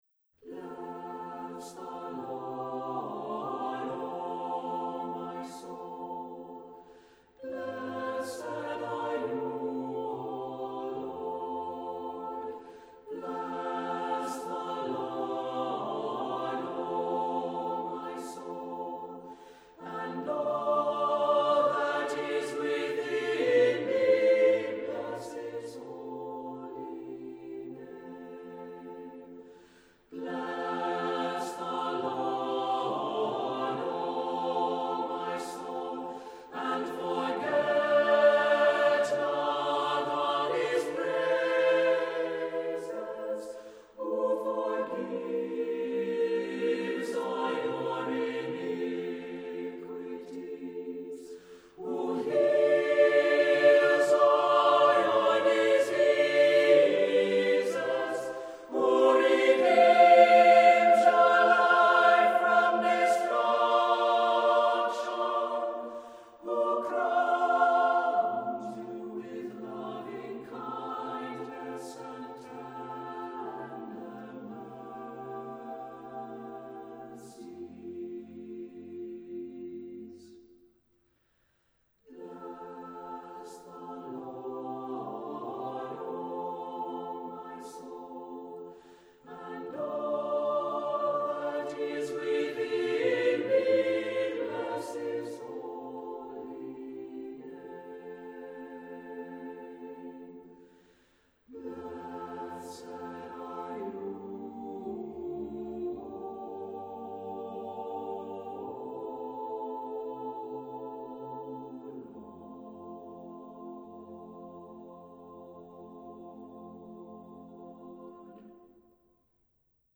Accompaniment:      A Cappella
Music Category:      Christian